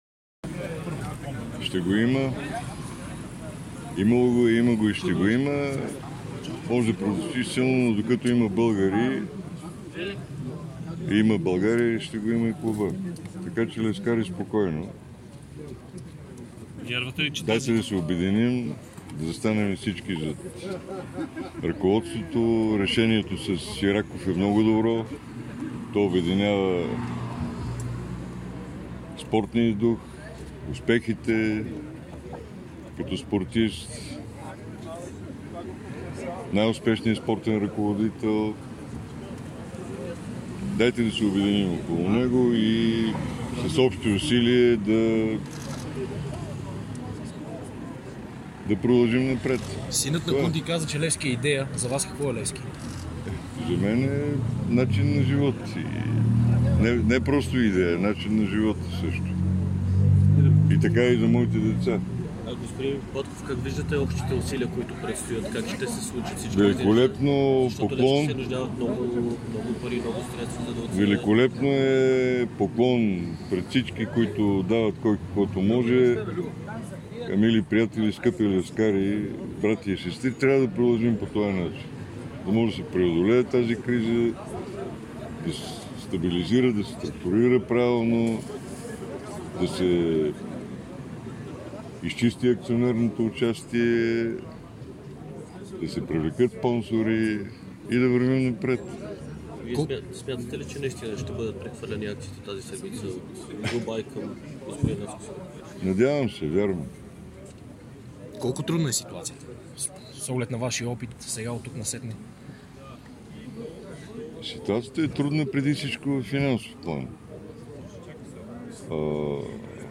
Бившият собственик на Левски Тодор Батков говори по време на шествието по повод рождения ден на клуба. Той обяви своята подкрепа към Наско Сираков.